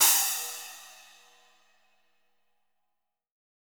-10 SPLASH.wav